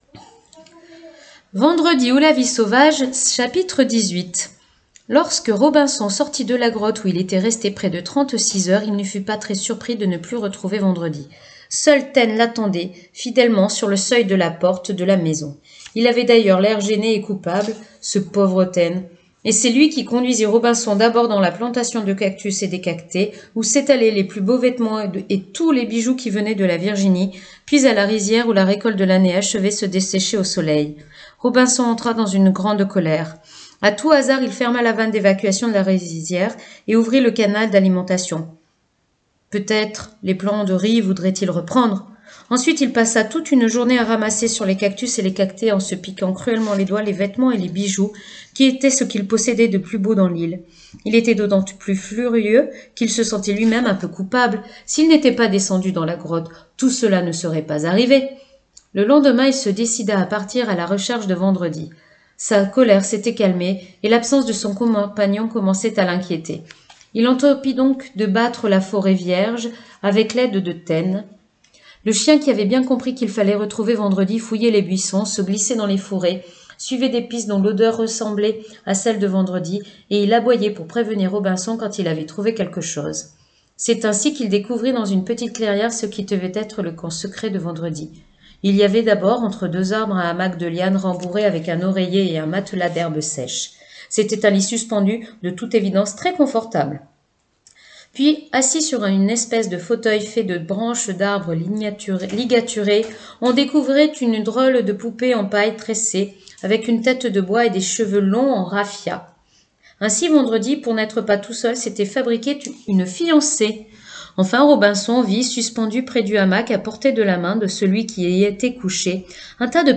Lecture du chapitre 18 de Vendredi ou la vie sauvage.